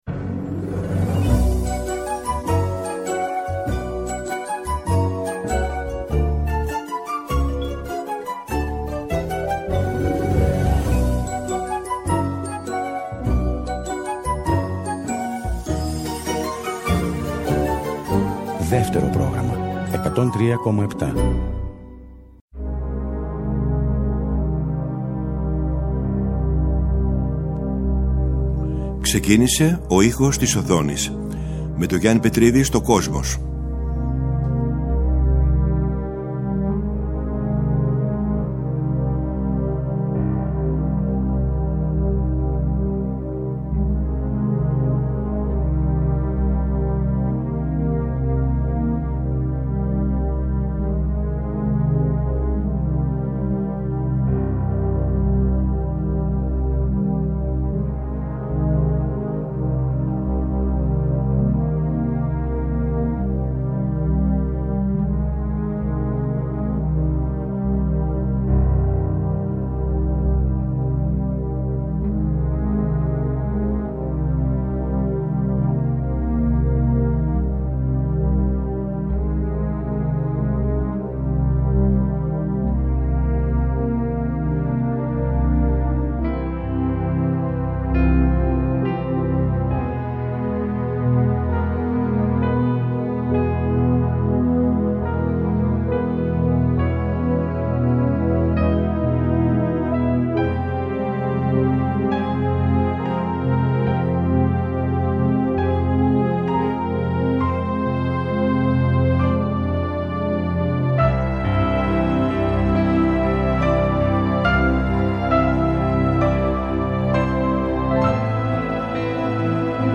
Στις εκπομπές αυτές θα ακουστούν μουσικές που έχουν γράψει μεγάλοι συνθέτες για τον κινηματογράφο, αλλά και τραγούδια που γράφτηκαν ειδικά για ταινίες.